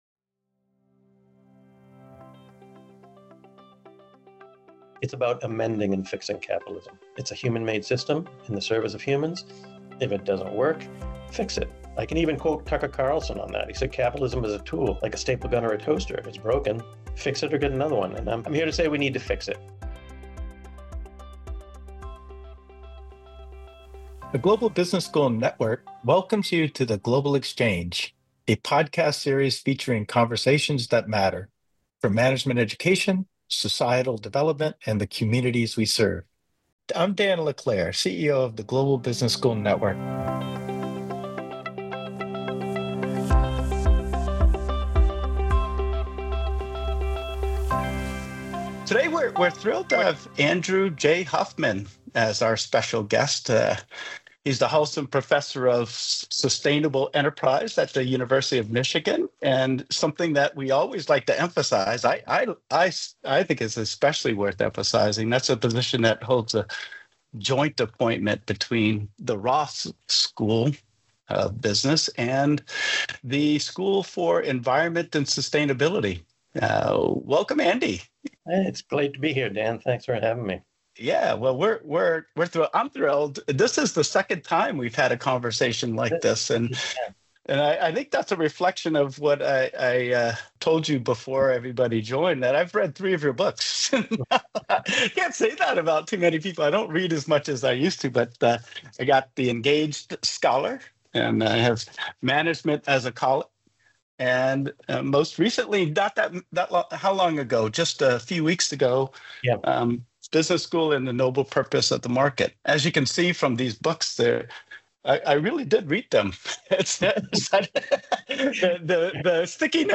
The Global Exchange: A Conversation